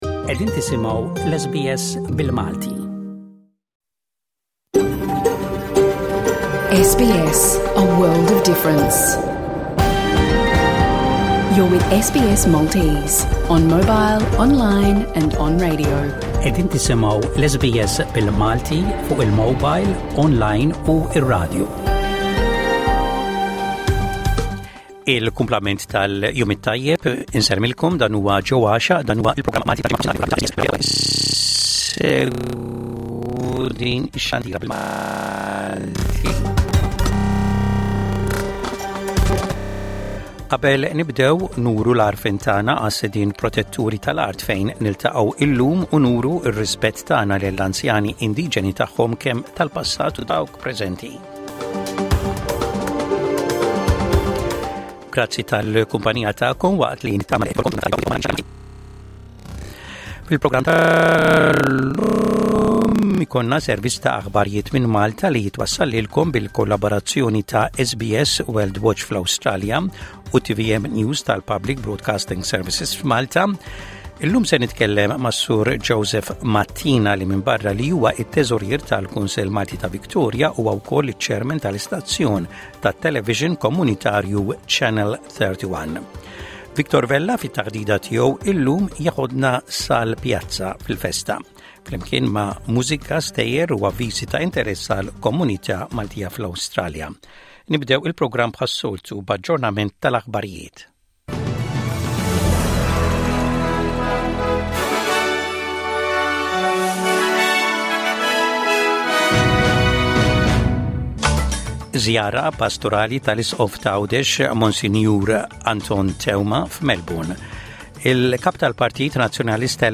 L-aħbarijiet mill-Awstralja u l-kumplament tad-dinja
Avviżi komunitarji.